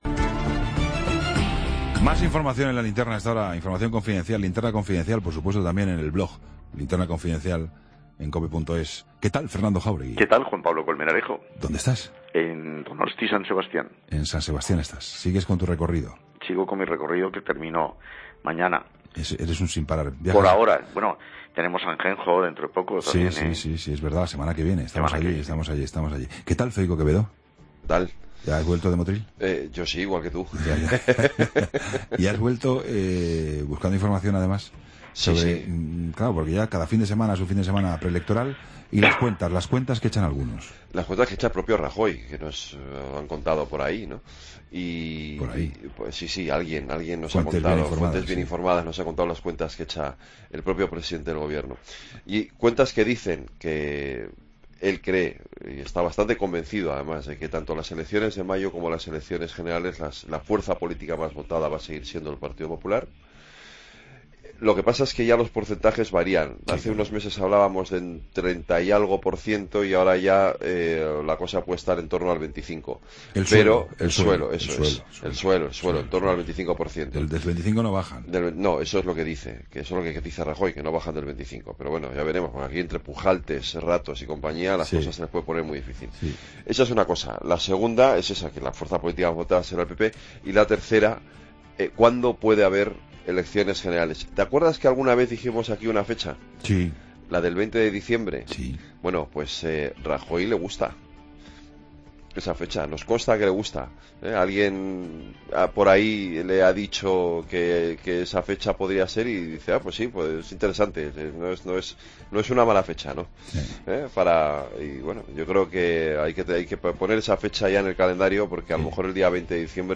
Entrevista a Carlos Rojas, portavoz del PP en el Parlamento andaluz